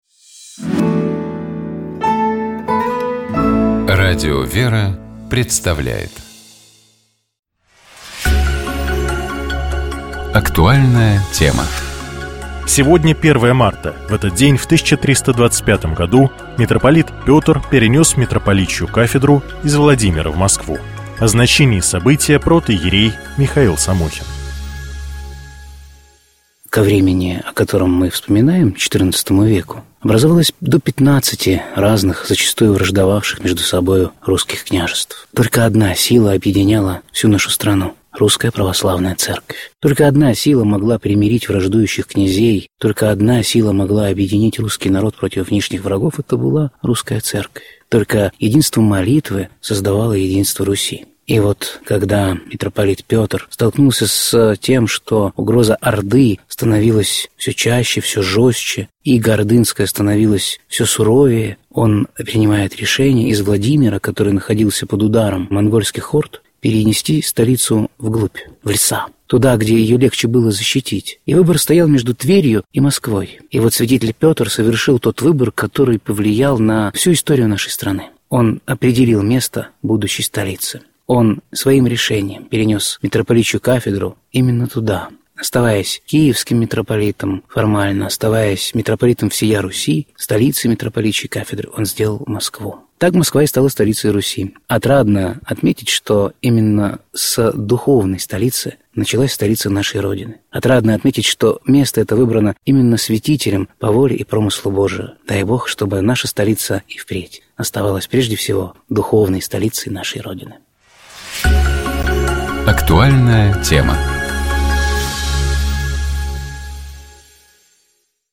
В нашей студии